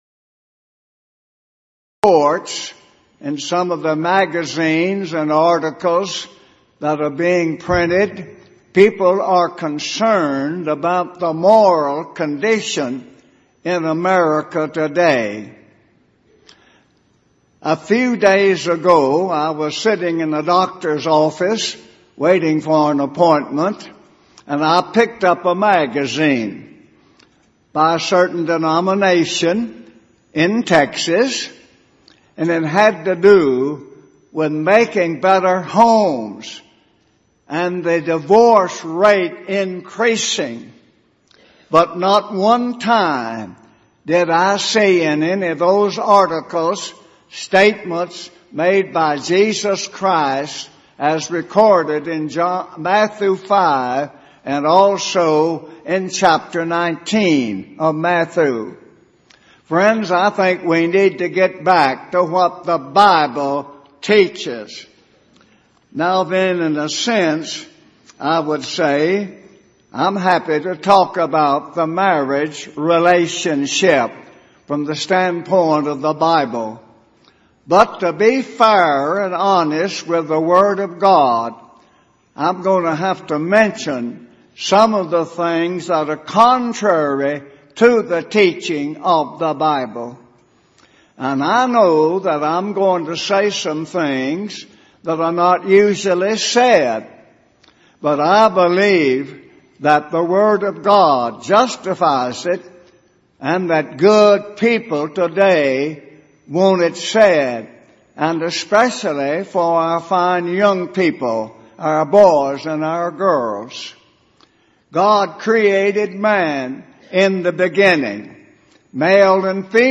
Event: 28th Annual Southwest Lectures
If you would like to order audio or video copies of this lecture, please contact our office and reference asset: 2009Southwest05